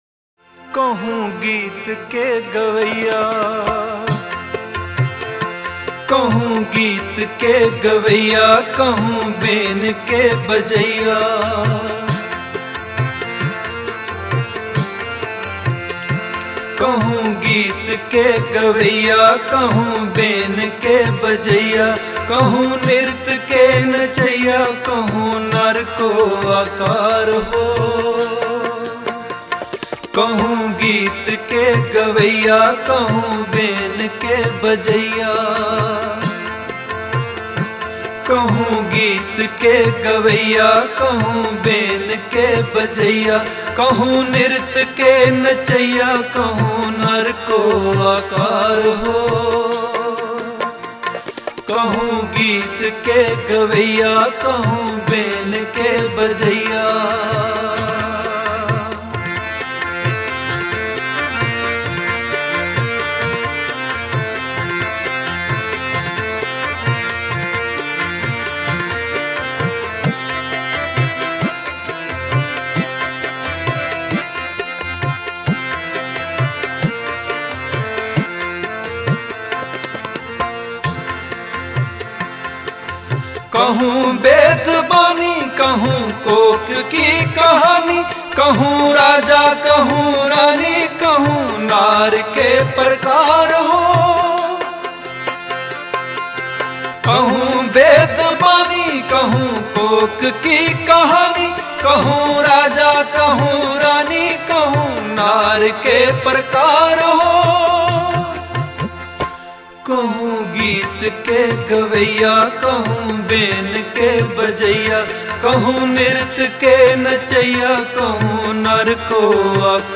Category: Shabad Gurbani